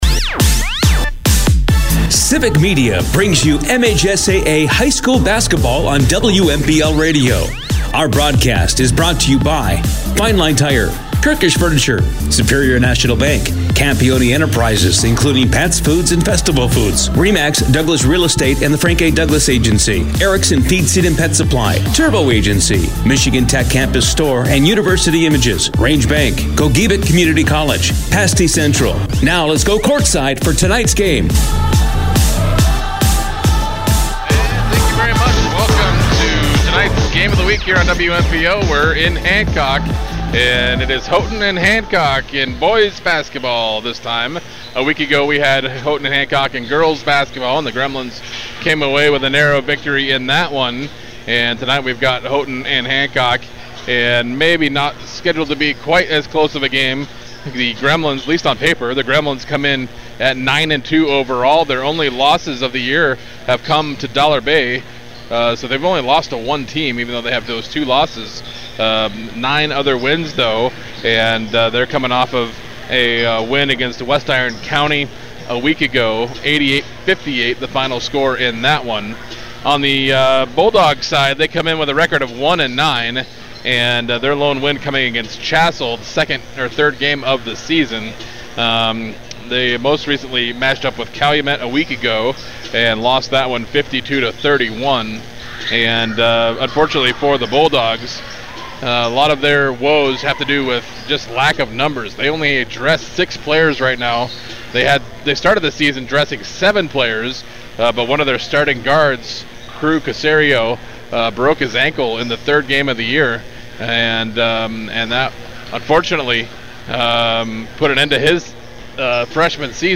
hs sports